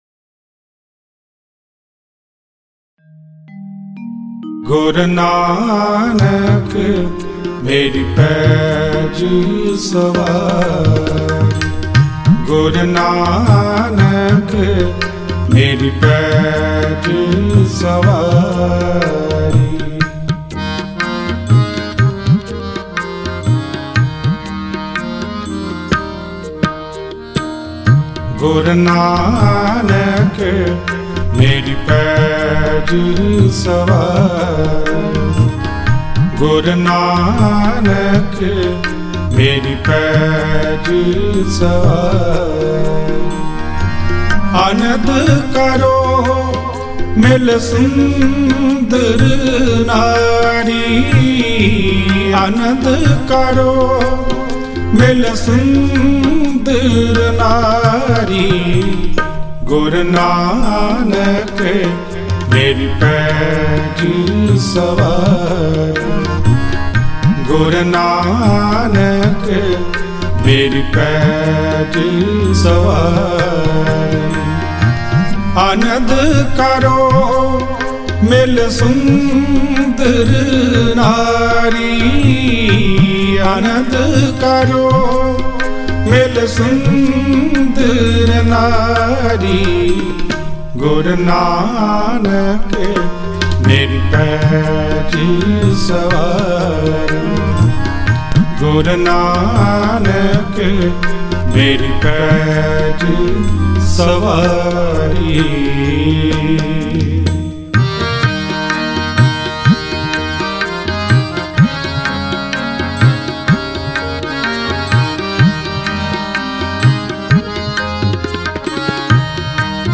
Guru nanak Dev Ji De Non Stop Shabad